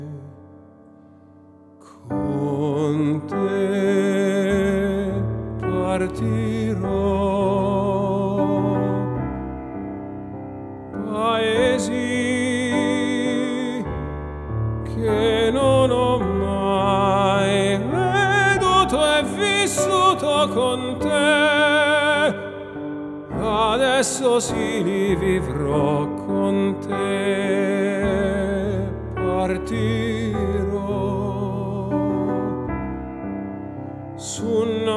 Classical Crossover
Жанр: Классика